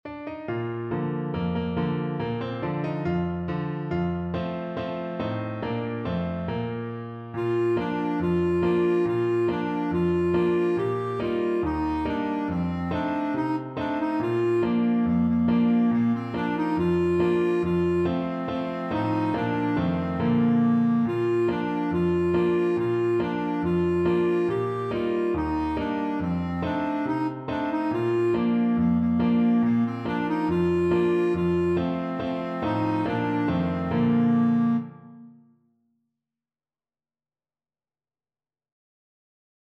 Clarinet version
Happily =c.140
4/4 (View more 4/4 Music)
Classical (View more Classical Clarinet Music)